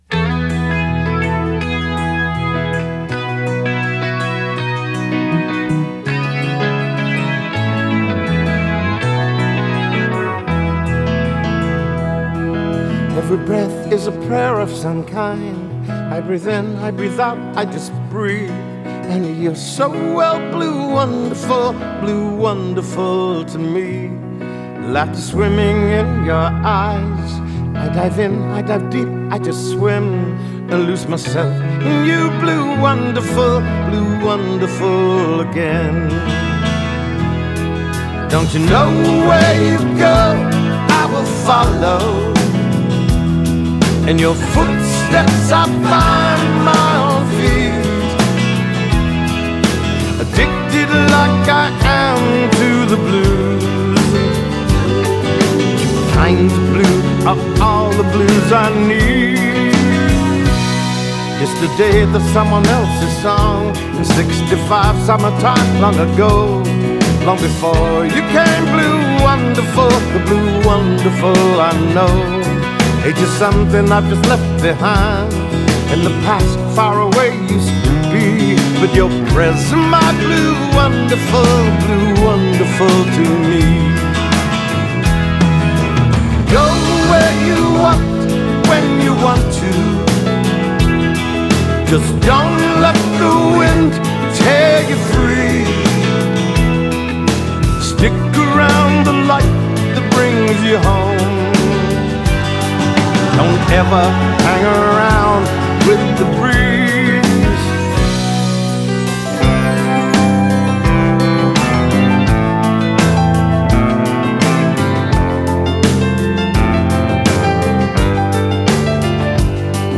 recorded at The Village in Los Angeles
Genre: Pop Rock, Classic Rock, Soft Rock